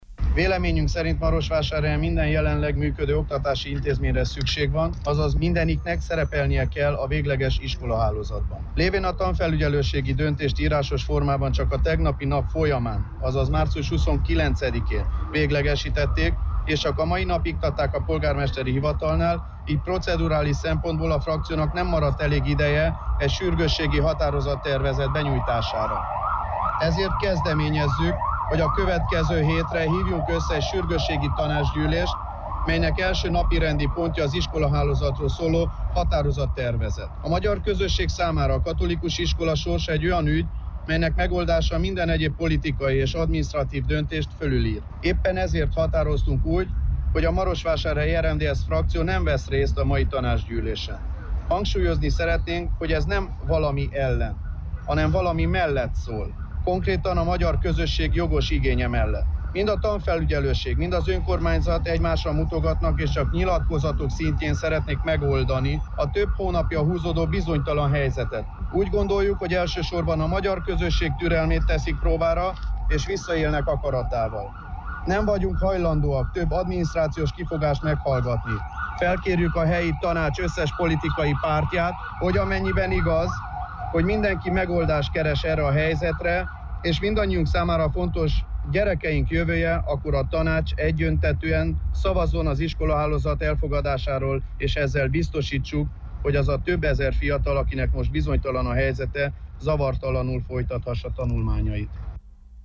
Csíki Zsolt az RMDSZ frakcióvezetőjét hallják.